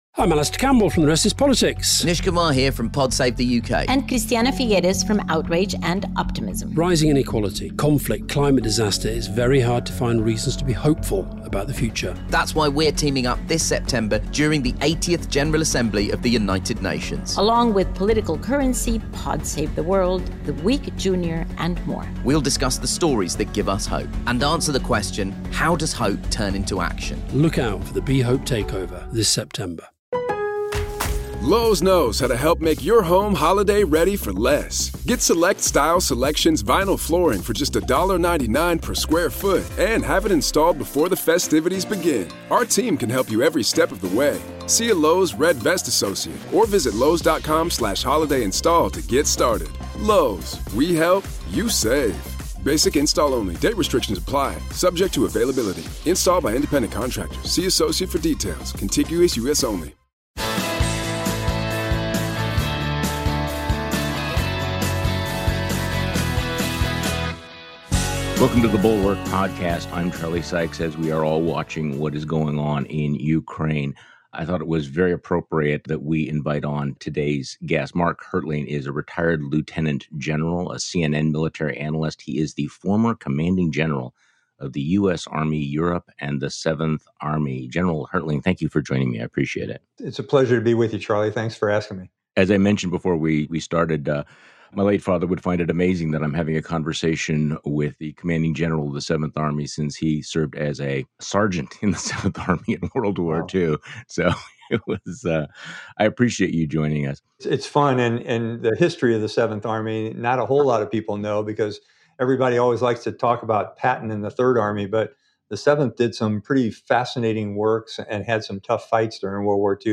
Americans say 'make it end,' but it won't until Putin accepts defeat in a war he can't win. Retired Lt. General Mark Hertling, the former commanding general of U.S. Army Europe and 7th Army, joins Charlie Sykes today.